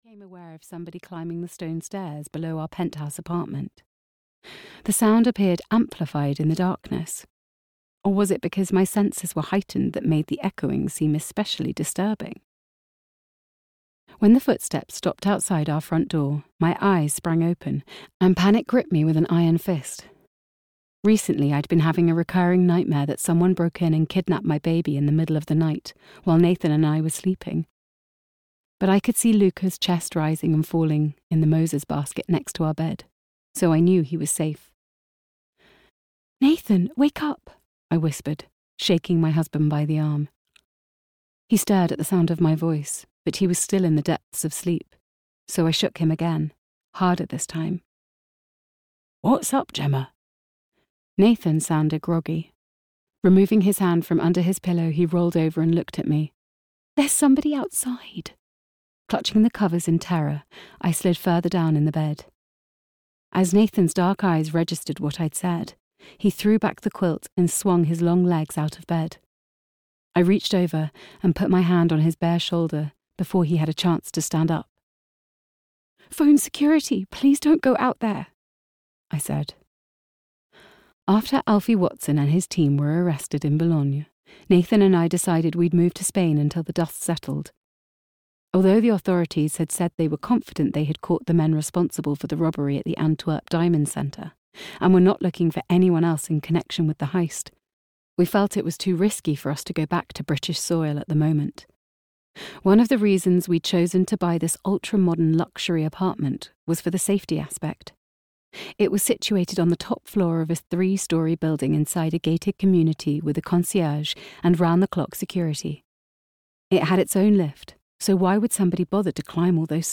Tangled Lives (EN) audiokniha
Ukázka z knihy